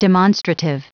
Prononciation du mot demonstrative en anglais (fichier audio)
Prononciation du mot : demonstrative